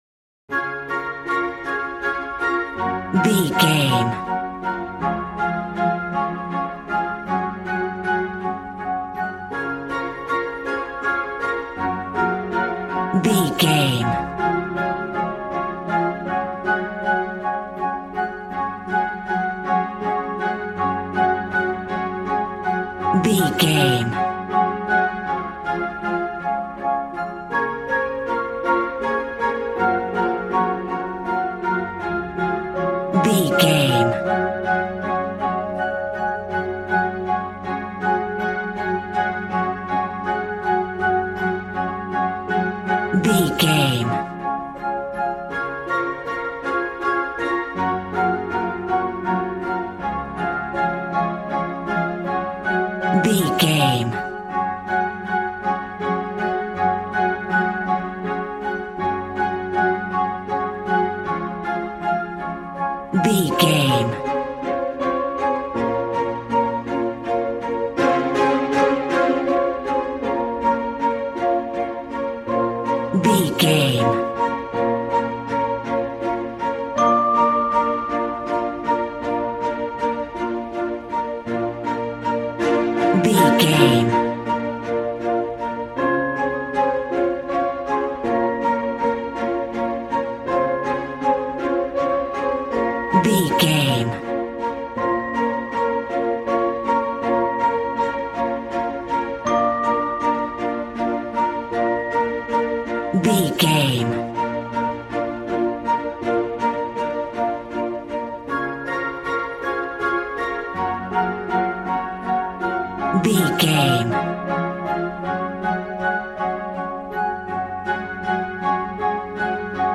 Regal and romantic, a classy piece of classical music.
Ionian/Major
D♭
regal
strings
violin
brass